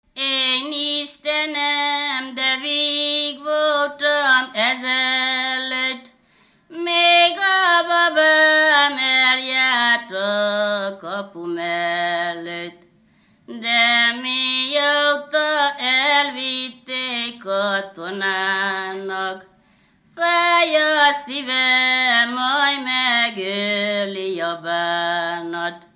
Alföld - Szabolcs vm. - Bököny
ének
Dallamtípus: Dudanóta (11 szótagos) 1
Stílus: 6. Duda-kanász mulattató stílus
Kadencia: 5 (1) 2 1